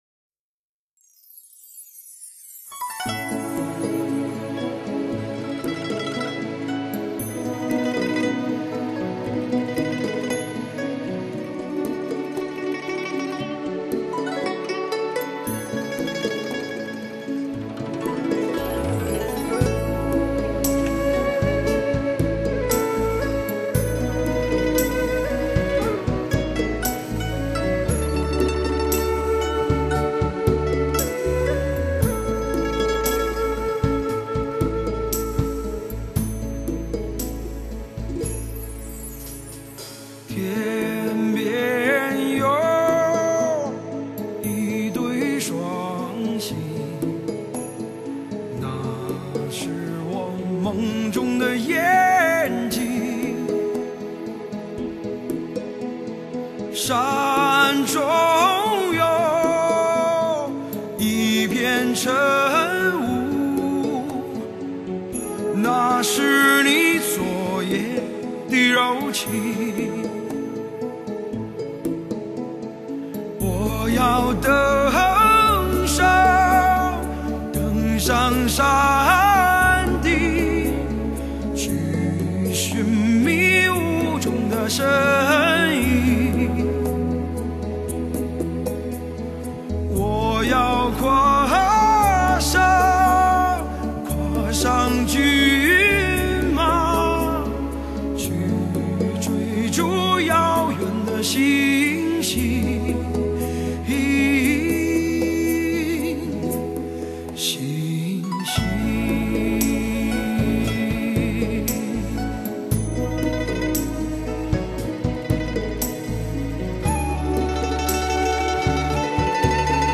慢慢地被这沧桑、豪迈、沉厚、感性的歌声一点点地诱拐了!